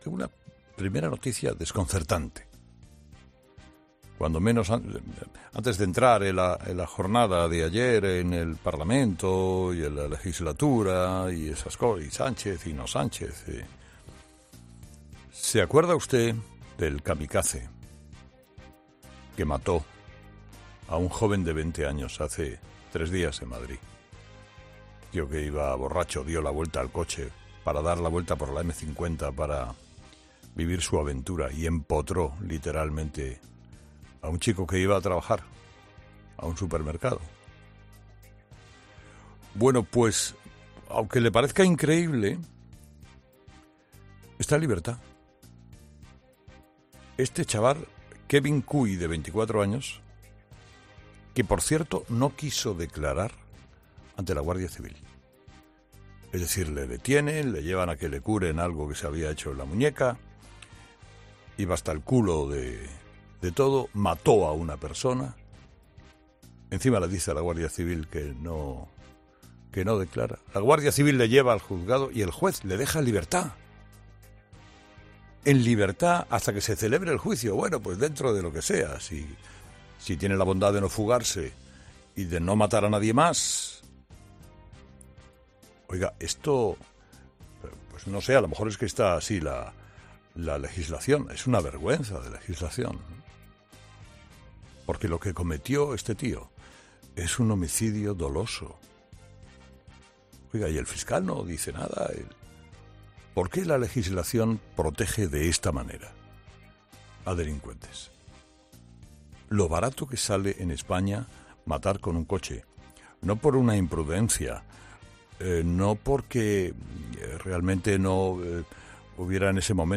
En su monólogo de este jueves, Herrera ha reaccionado ante la noticia de la puesta en libertad del kamikaze.